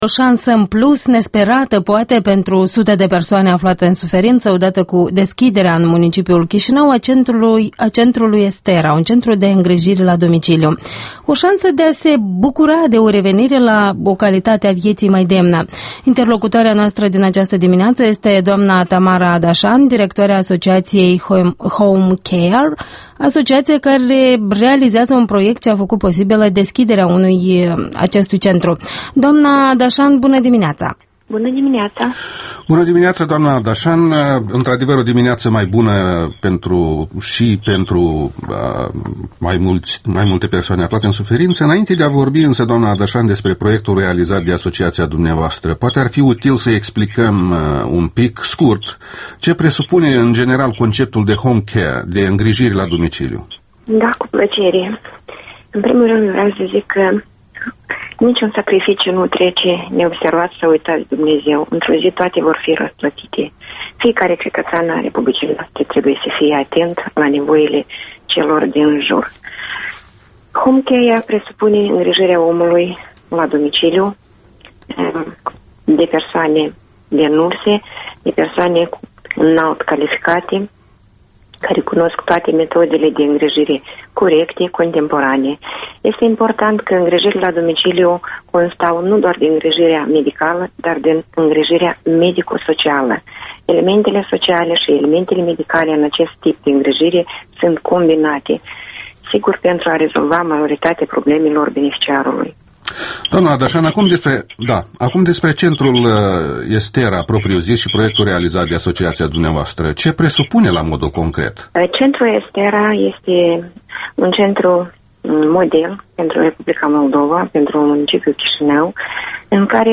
Interviul matinal la Europa Liberă